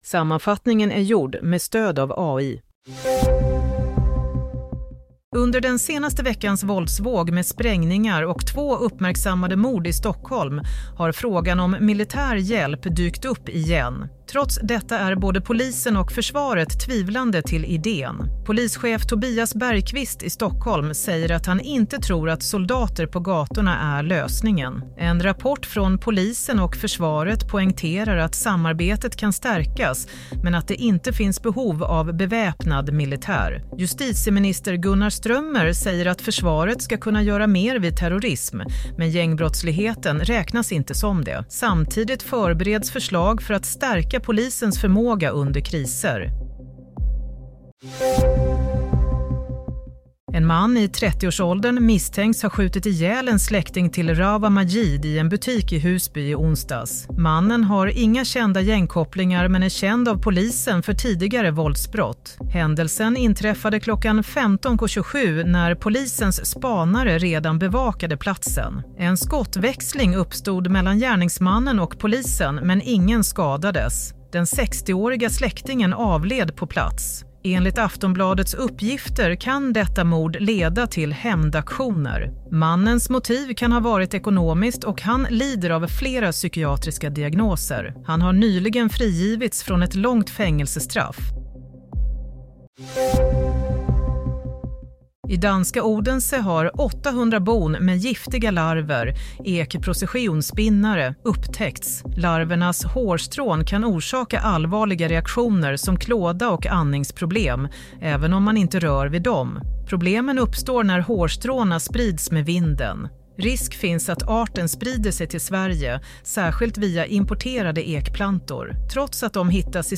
Nyhetssammanfattning – 30 januari 22:00